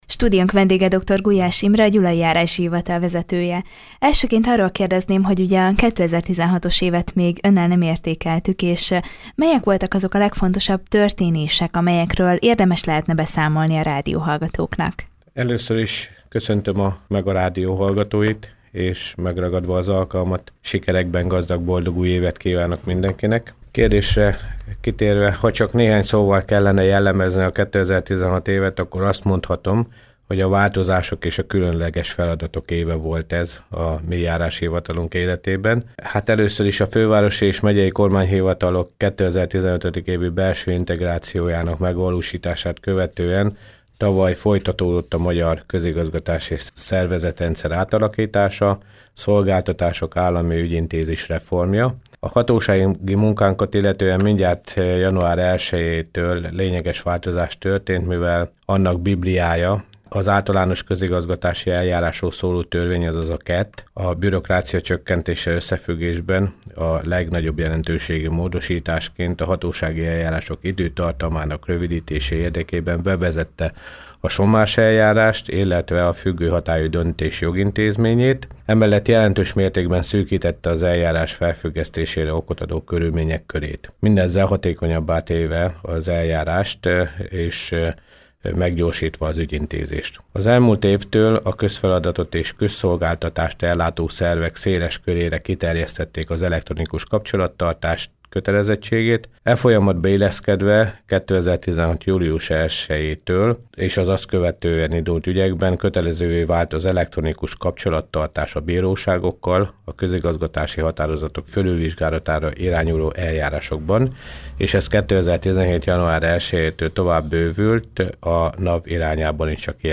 Post navigation Előző hír Mesedoktorok a Jókai Színházban Következő hír Békéscsabán virágzik a kulturális élet KATEGÓRIA: Interjúk